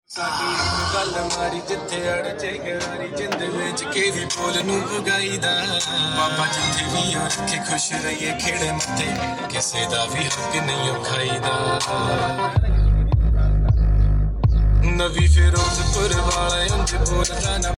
Mehran sound system independent boxes sound effects free download